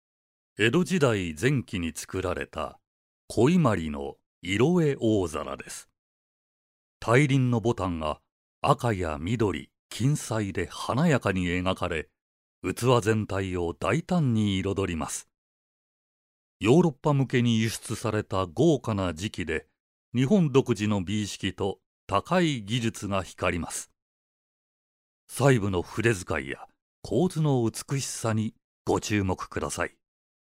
落ち着いた／穏やか
知的／クール
アルト／重厚感
どことなく和を感じさせる低音。
ボイスサンプル3（博物館音声ガイド） [↓DOWNLOAD]